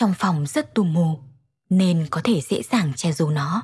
nữ miền Bắc